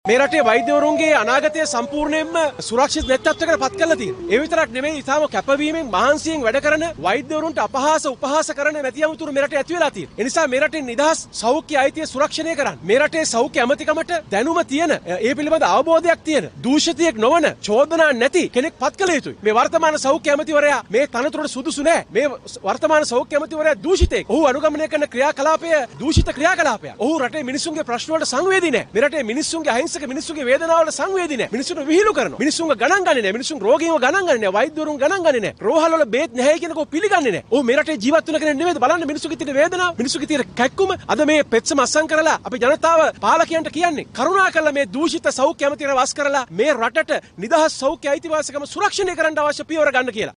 මේ, එහිදි අදහස් දැක්වූ කළුතර දිස්ත්‍රික්කයේ සමගි ජනබලවේගය පක්ෂයේ සංවිධායක අජිත් පී.පෙරේරා මහතා.